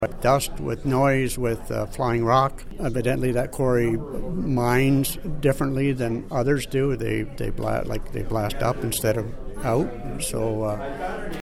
Warden Rick Phillips spoke with Quinte News following the council meeting.